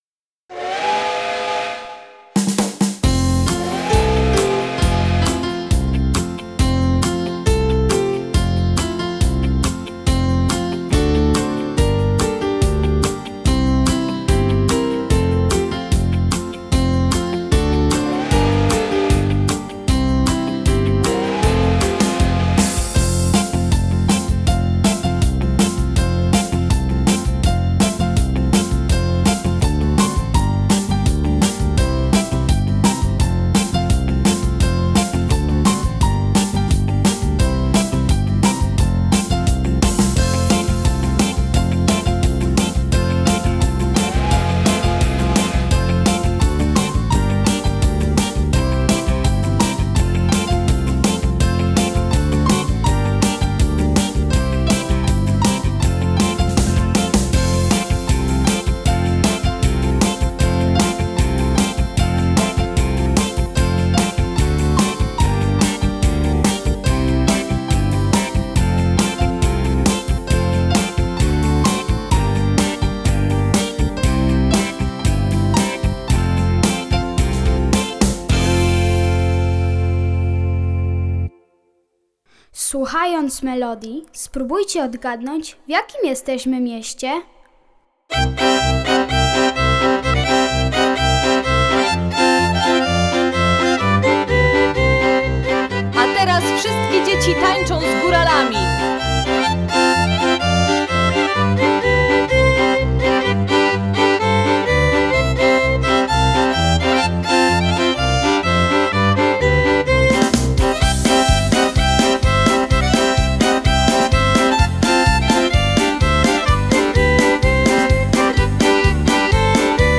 piosenki dla dzieci